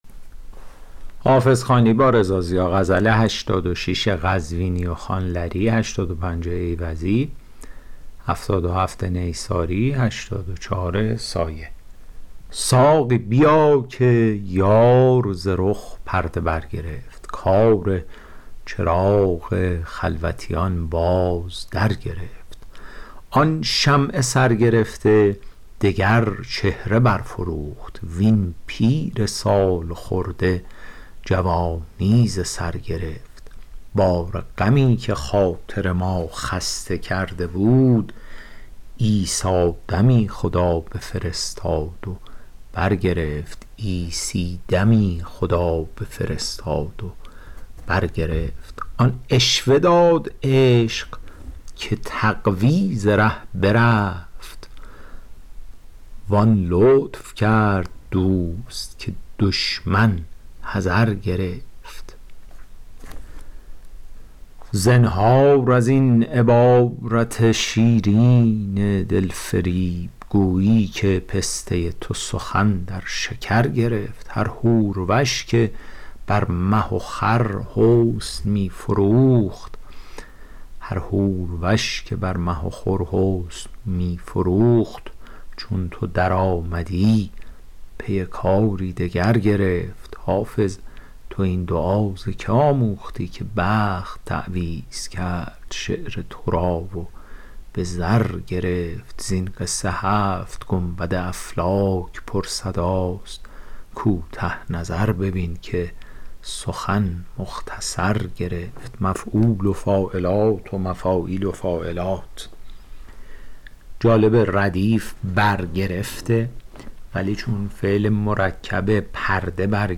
شرح صوتی غزل شمارهٔ ۸۶